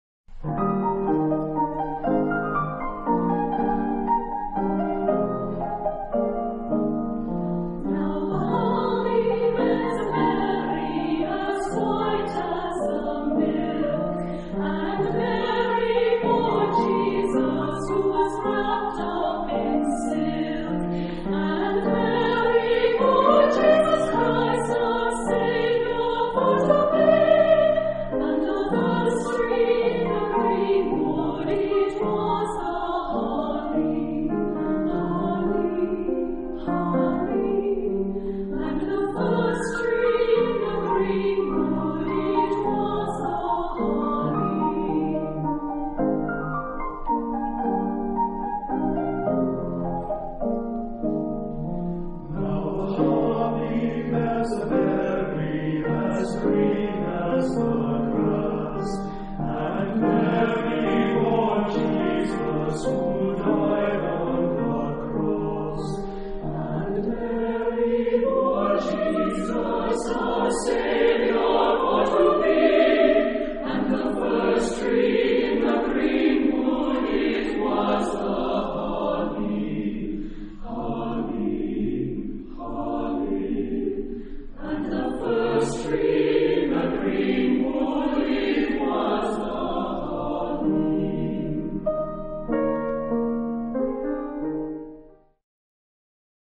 interprété par Susquehanna Chorale (USA)
Genre-Style-Forme : Sacré ; Chant de Noël Type de choeur : SATB (4 voix mixtes )
Instruments : Piano (1)
Tonalité : mi bémol majeur
traditional Cornish carol